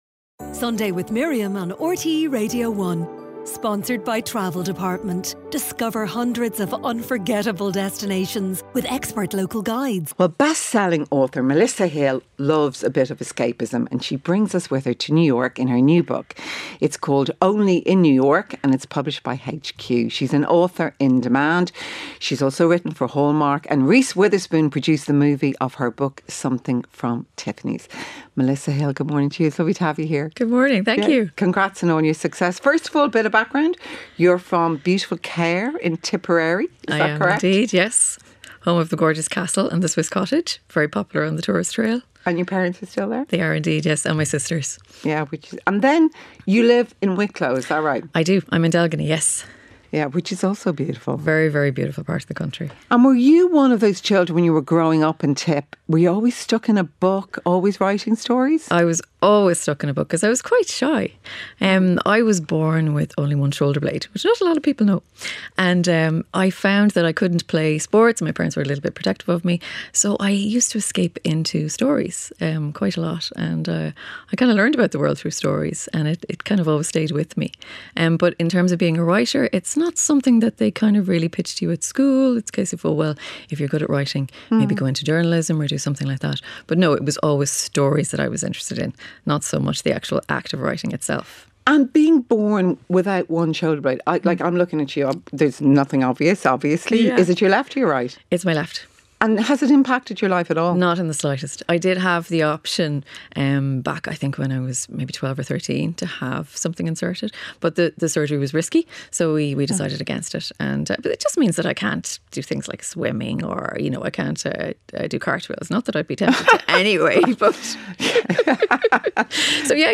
The best-selling author chats about her childhood in Cahir, Tipperary, co-writing thrillers with her husband, writing scripts for Hallmark movies, working with Reese Witherspoon and her latest novel ‘Only in New York’ (published by HQ)